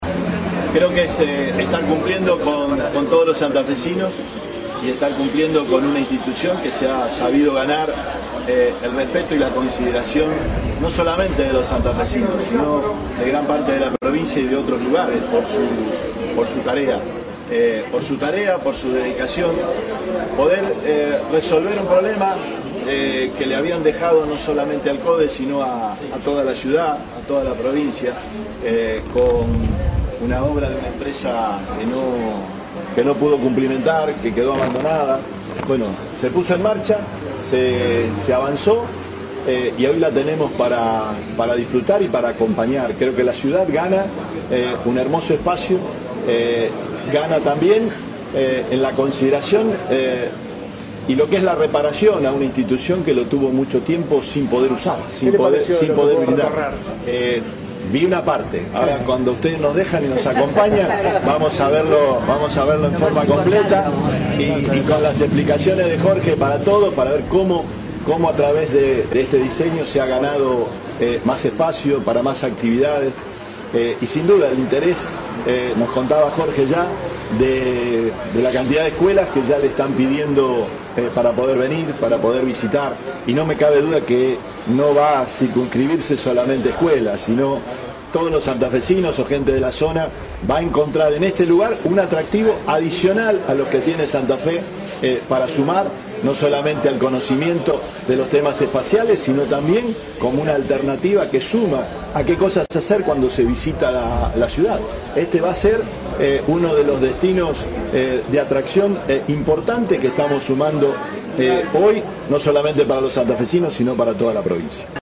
Declaraciones Perotti CODE